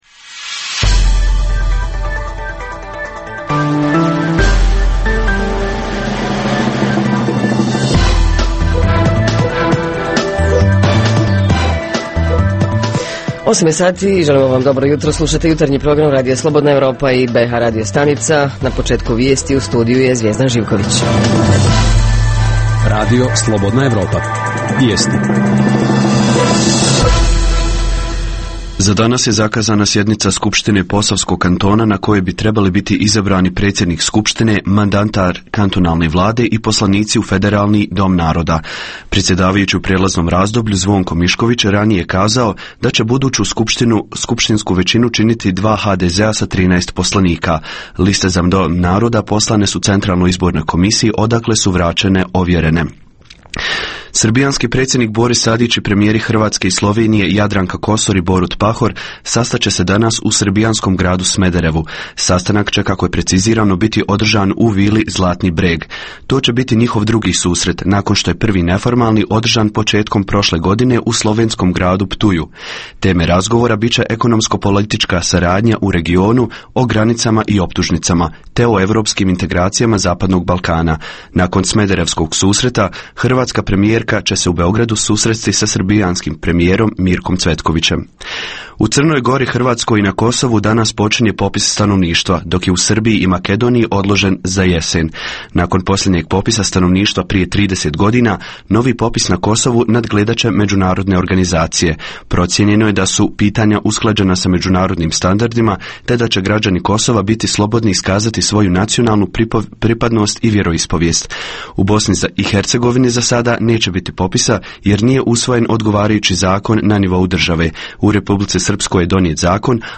Ovog jutra govorimo o deponijama za odlaganje smeća i firmama koje se bave ovim poslom Reporteri iz cijele BiH javljaju o najaktuelnijim događajima u njihovim sredinama. Redovna rubrika Radija 27 petkom je “Za zdrav život". Redovni sadržaji jutarnjeg programa za BiH su i vijesti i muzika.